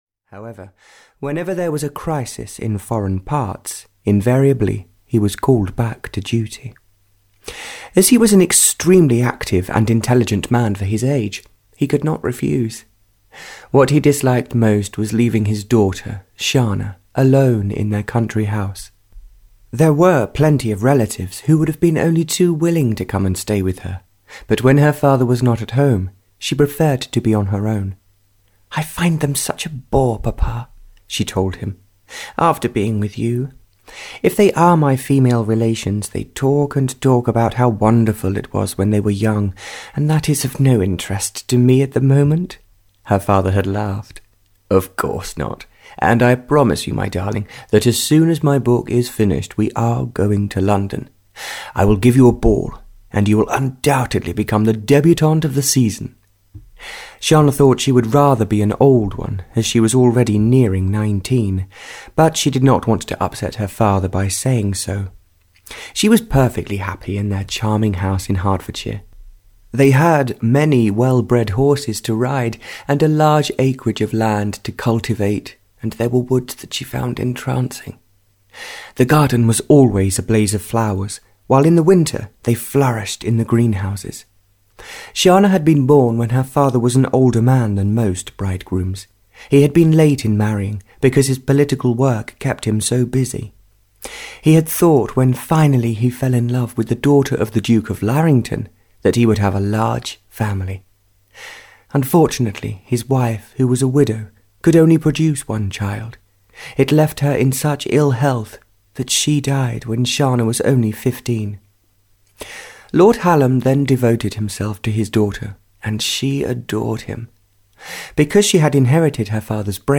Audio knihaJourney to Love (Barbara Cartland’s Pink Collection 37) (EN)
Ukázka z knihy